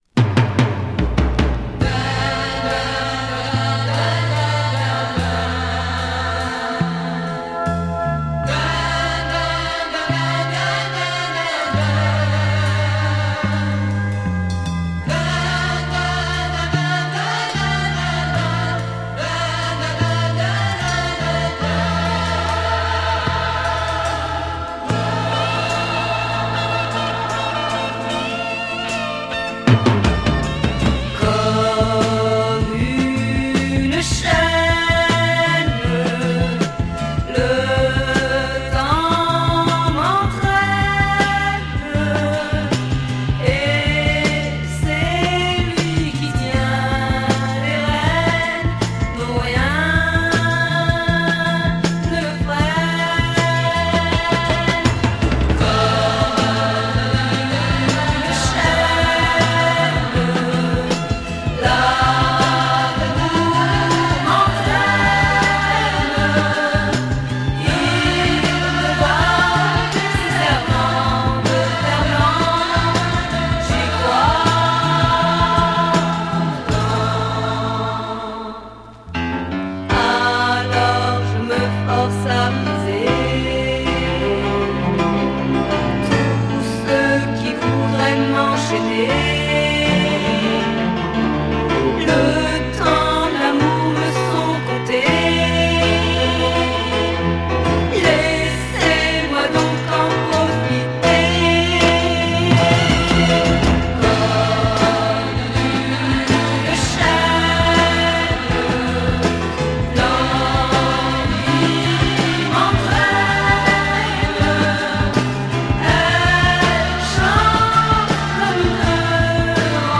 French Female Pop-sike folk